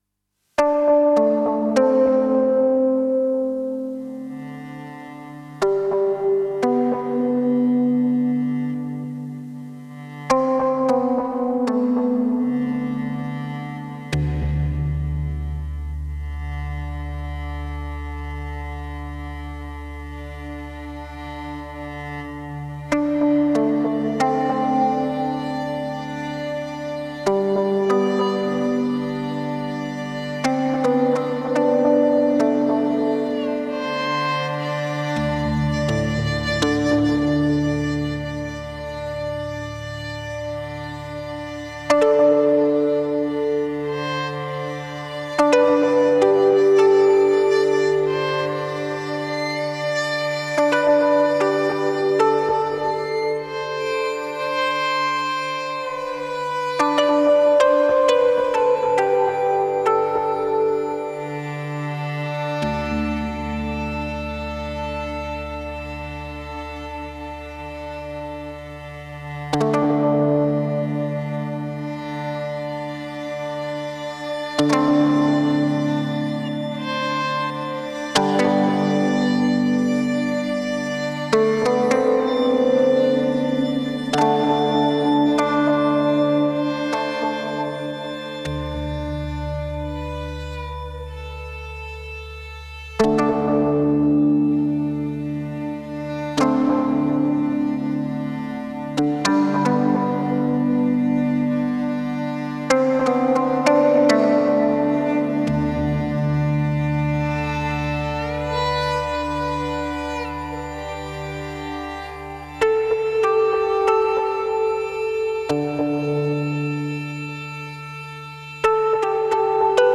VIOLIN 1 ONLY